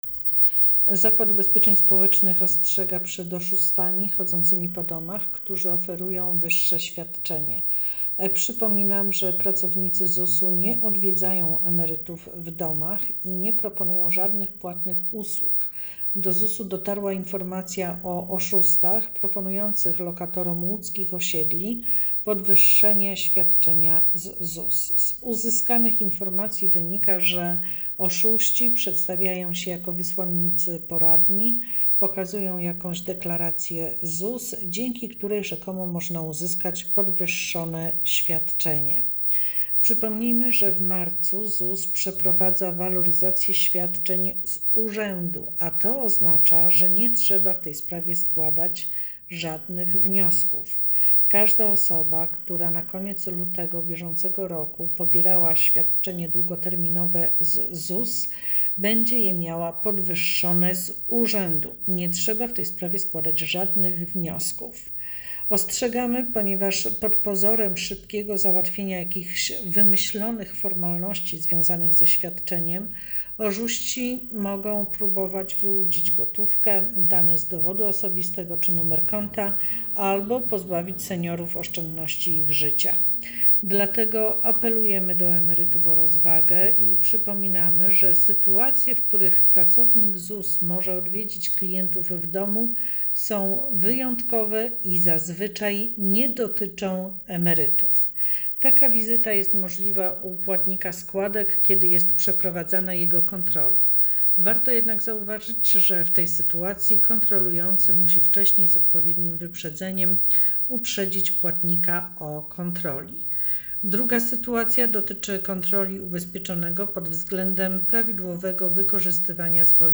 Komunikat audio - audiodeskrypcja [51].mp3 mp3 1,99 MB